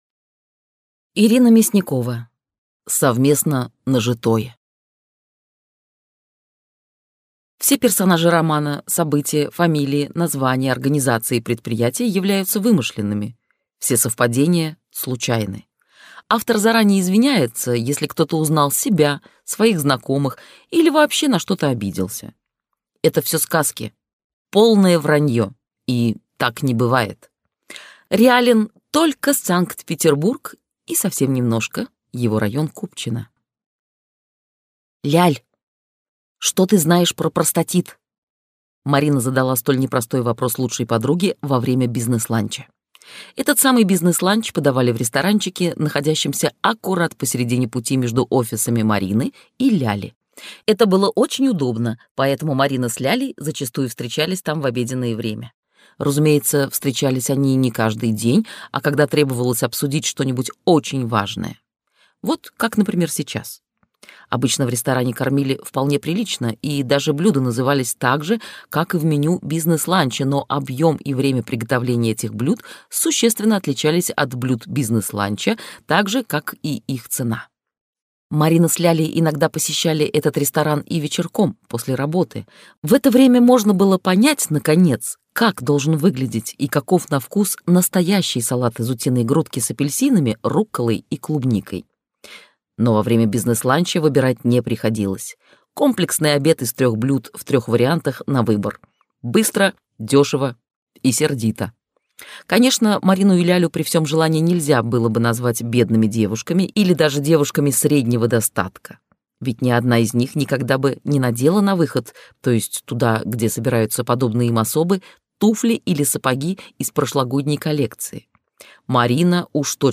Прослушать фрагмент аудиокниги Совместно нажитое Ирина Мясникова Произведений: 2 Скачать бесплатно книгу Скачать в MP3 Вы скачиваете фрагмент книги, предоставленный издательством